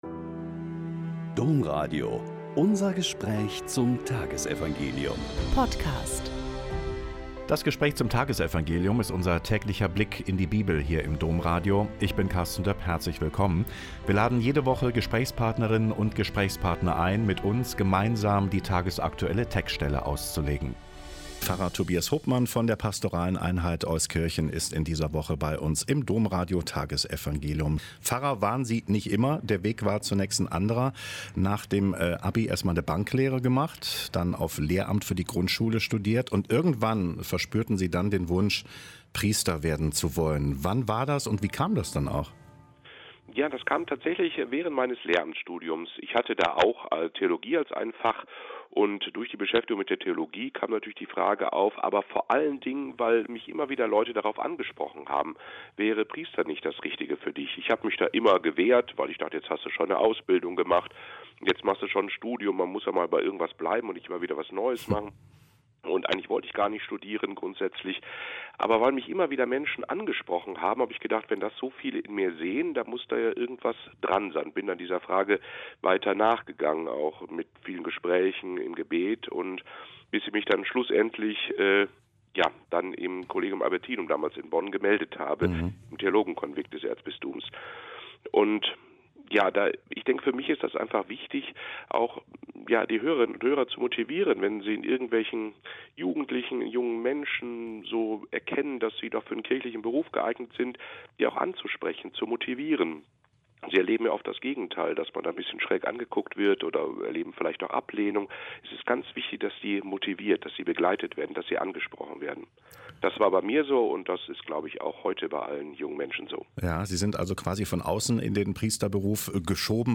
Mt 9,14-15 - Gespräch